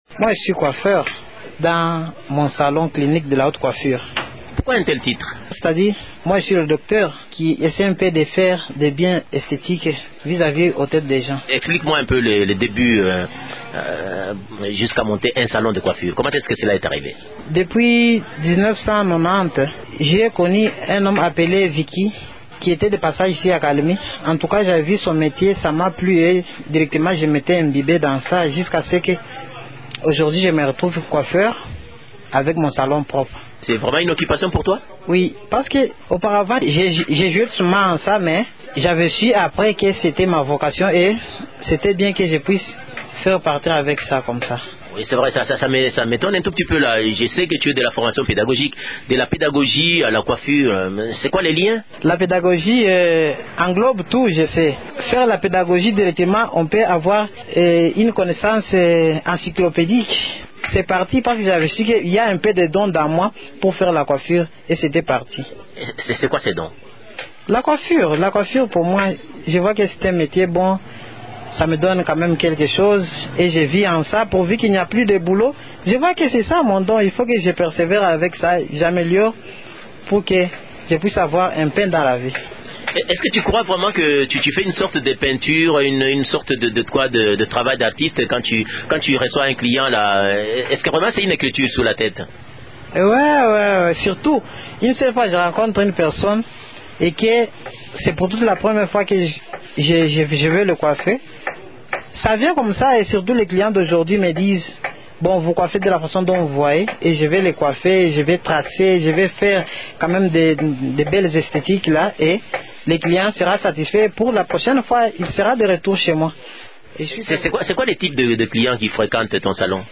Je vous propose cet entretien.